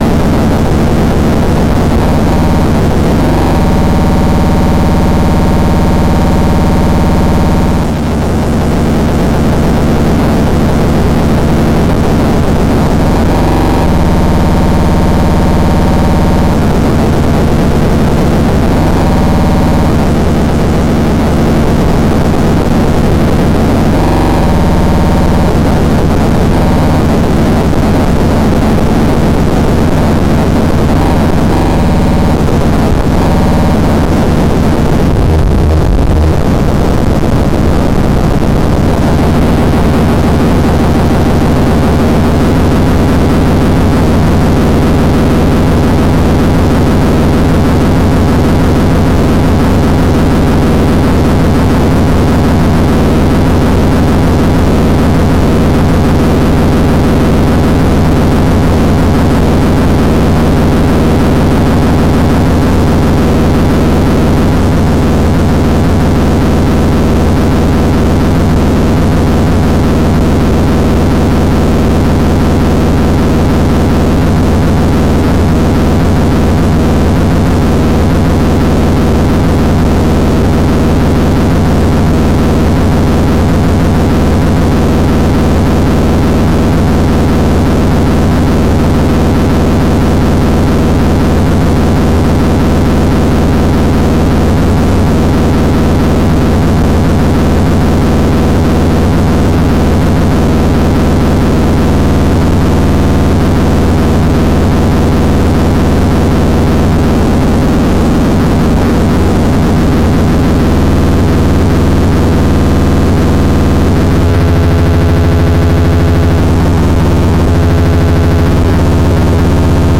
Moar Lyra noise